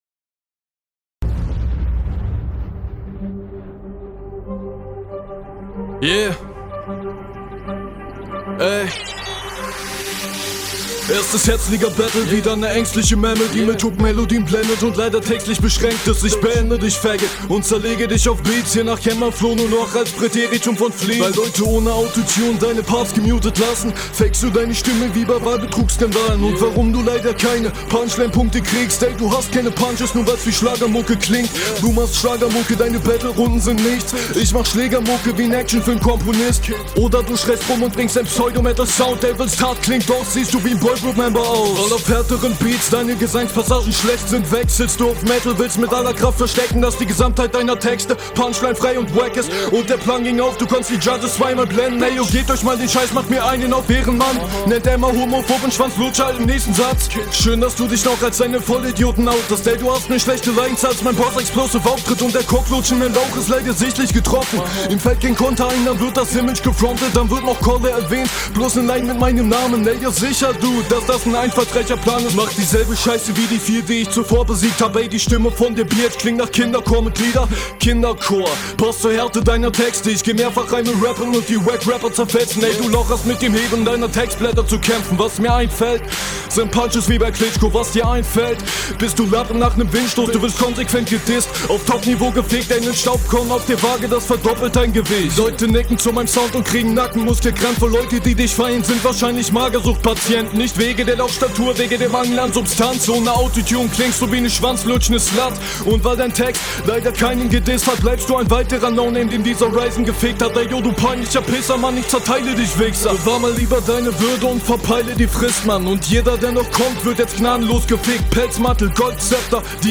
hmm, flowst schonmal nicht verkehrt, hast immer wieder coole patterns drin. manchmal ists nicht ganz …